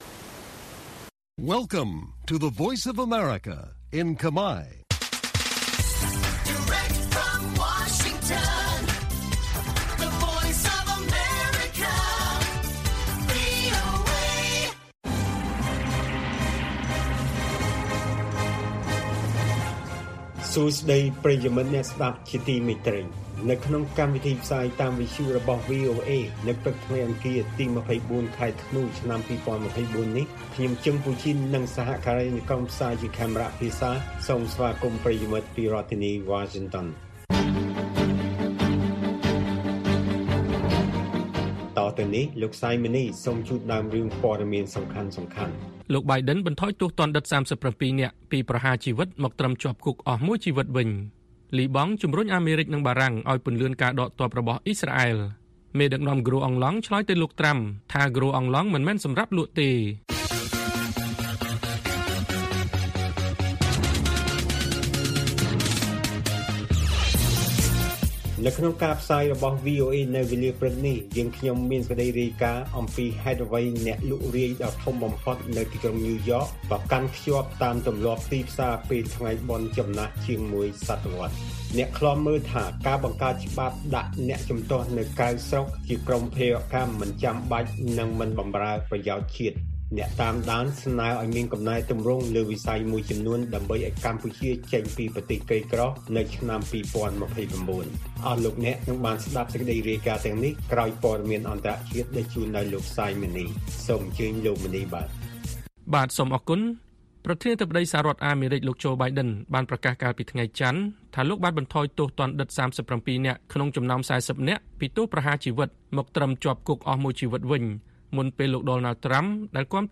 ព័ត៌មានពេលព្រឹក ២៤ ធ្នូ៖ អ្នកឃ្លាំមើល៖ ការបង្កើតច្បាប់ដាក់អ្នកជំទាស់នៅក្រៅស្រុកជា«ក្រុមភេរវកម្ម»មិនចាំបាច់